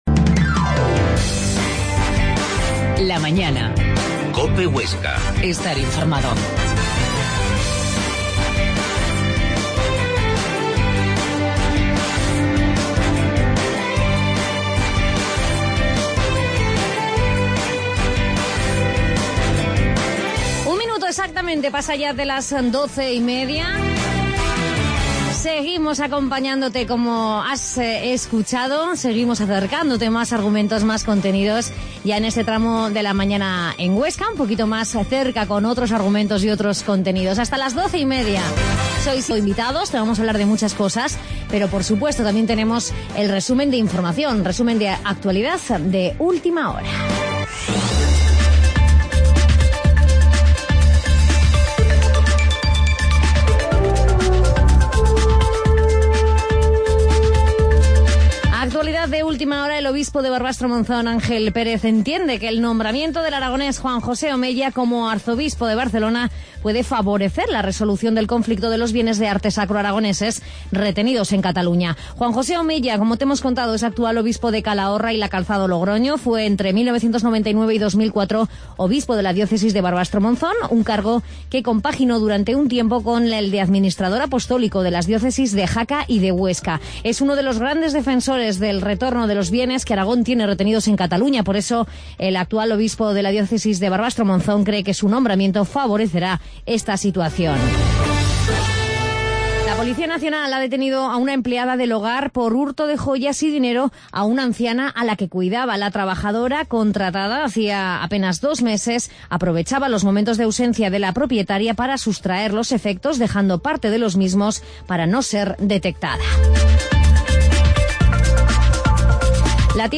La Mañana en COPE Huesca - Magazine